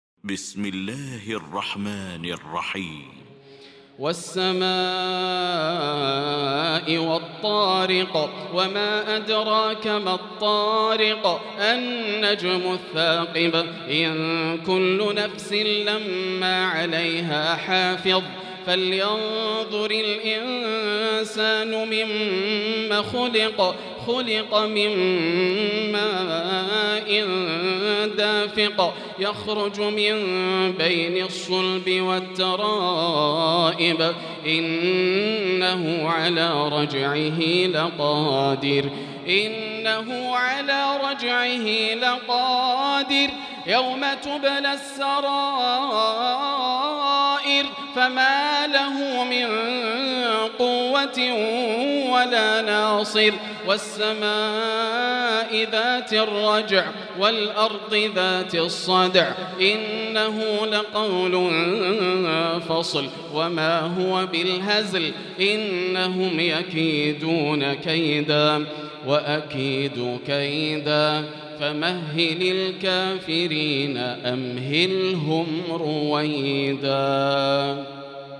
المكان: المسجد الحرام الشيخ: فضيلة الشيخ ياسر الدوسري فضيلة الشيخ ياسر الدوسري الطارق